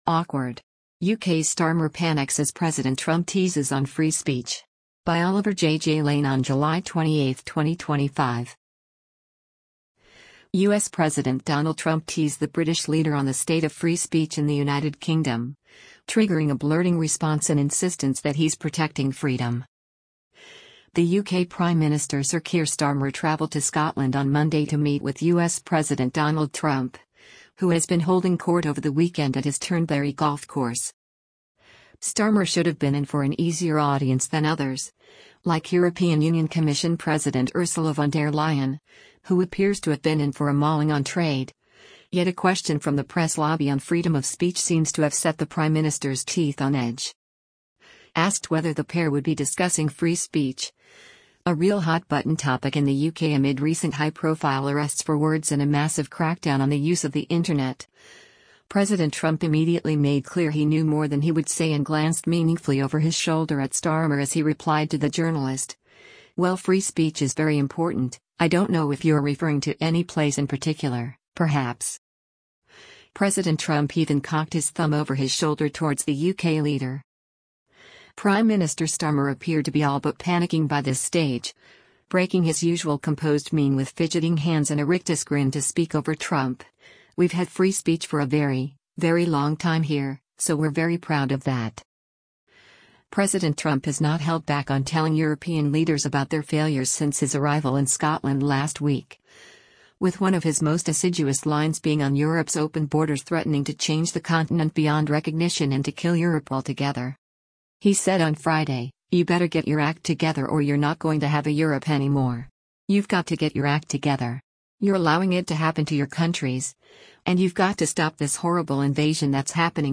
Asked whether the pair would be discussing free speech — a real hotbutton topic in the UK amid recent high-profile arrests for words and a massive crackdown on the use of the internet — President Trump immediately made clear he knew more than he would say and glanced meaningfully over his shoulder at Starmer as he replied to the journalist: “well free speech is very important, I don’t know if you’re referring to any place in particular, perhaps”.
Prime Minister Starmer appeared to be all but panicking by this stage, breaking his usual composed mien with fidgeting hands and a rictus grin to speak over Trump: “we’ve had free speech for a very, very long time here, so we’re very proud of that”.